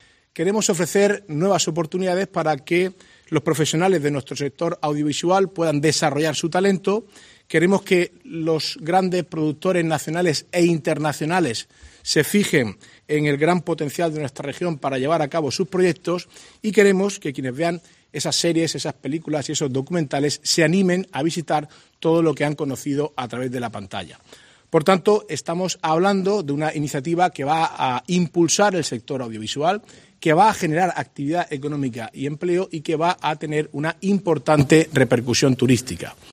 Marcos Ortuño, consejero de Presidencia, Turismo, Cultura, Juventud, Deportes y Portavocía